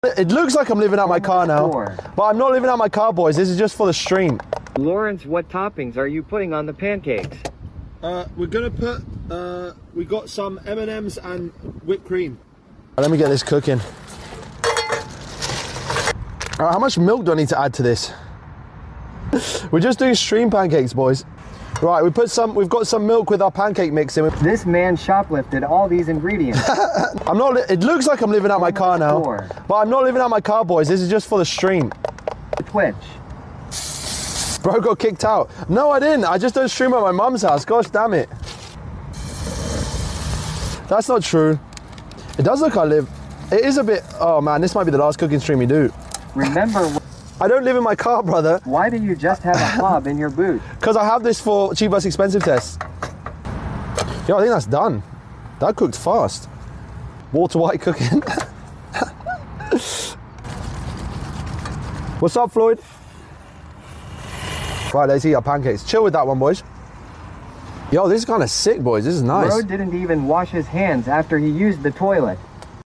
Cheap vs expensive guy cooking pancakes in a car park